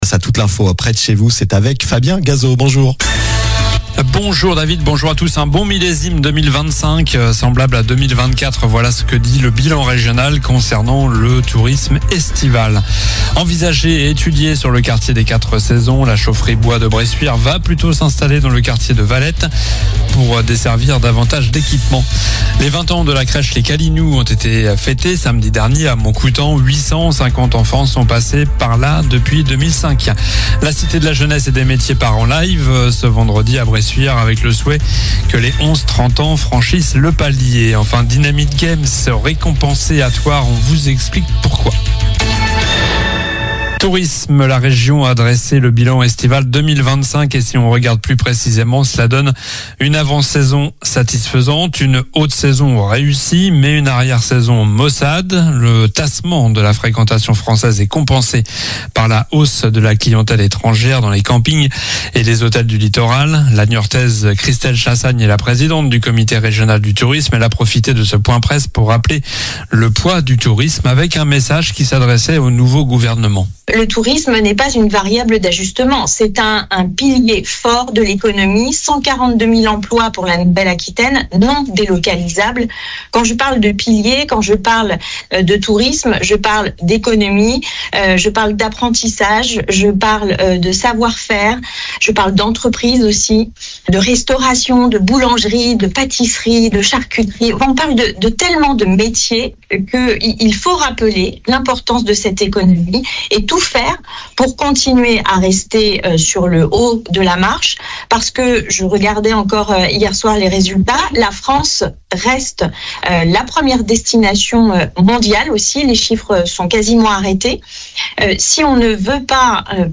Journal du mardi 14 octobre (midi)